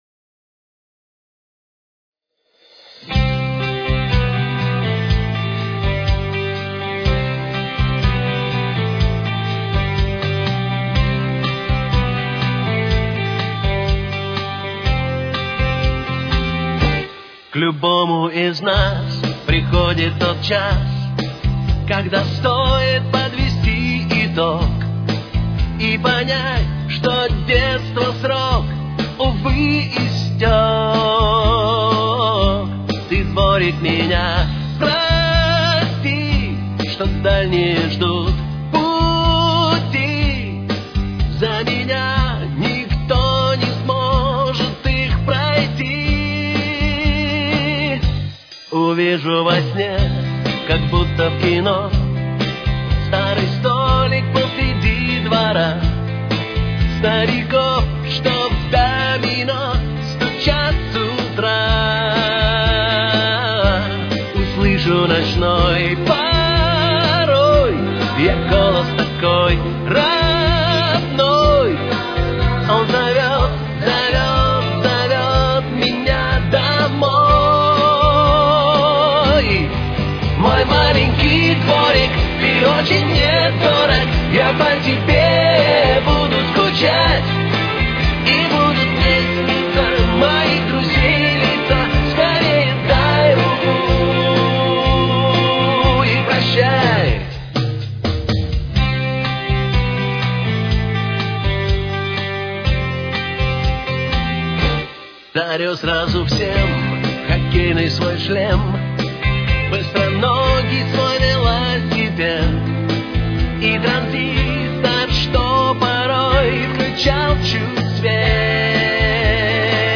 с очень низким качеством (16 – 32 кБит/с)
До мажор. Темп: 126.